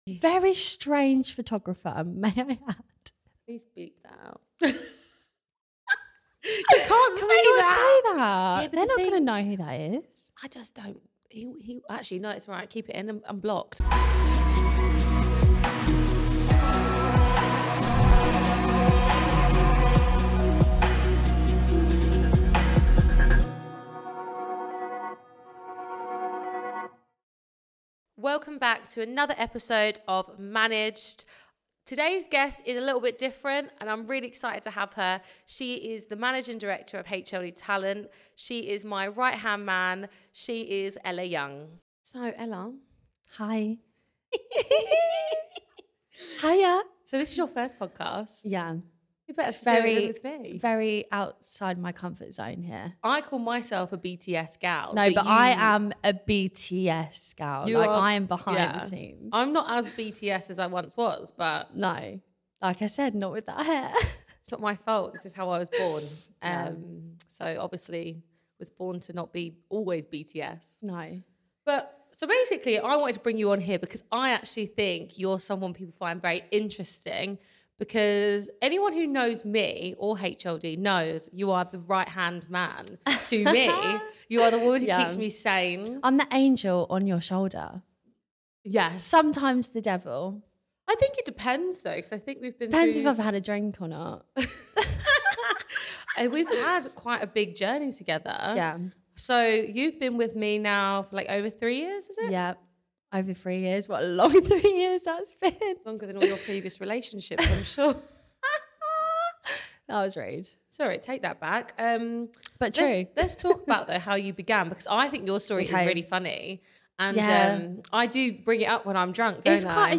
Managed is a podcast/ digital show interviewing industry leaders across media, business, sports and more who found success at a young age.